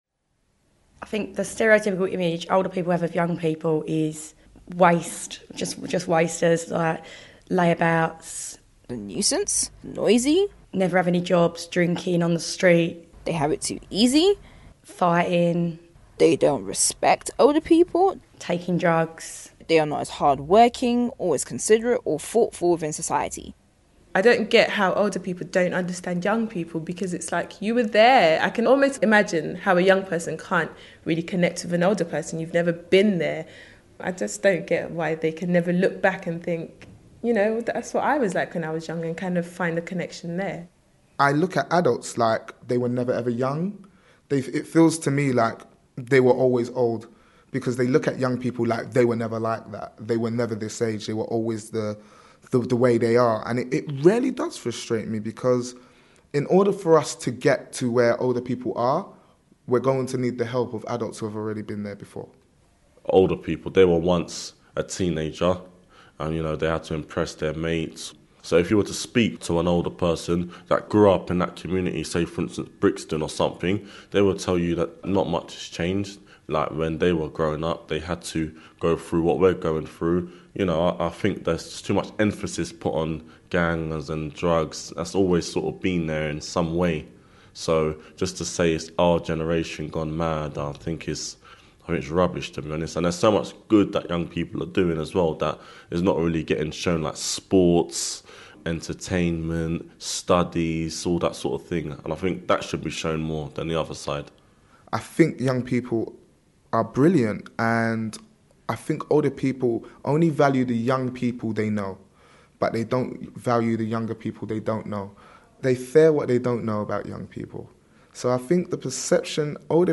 In this audioboo recorded as part of the project young people shared their views.